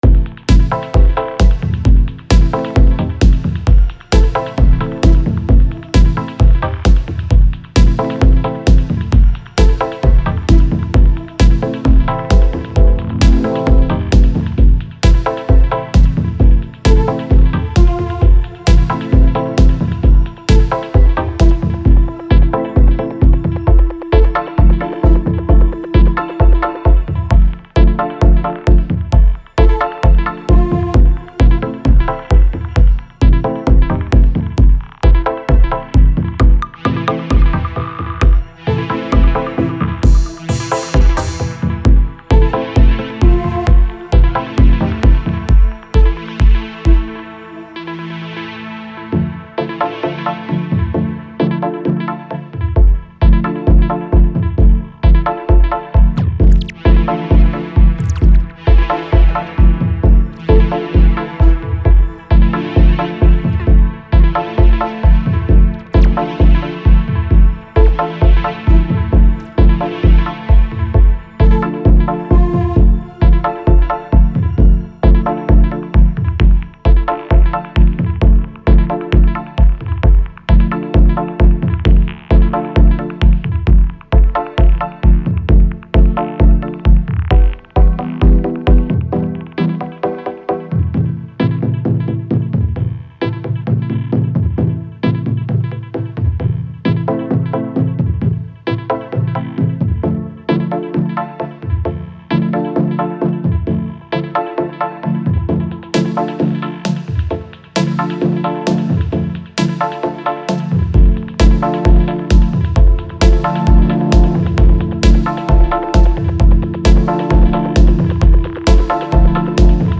Auf 132 BPM beschleunigen.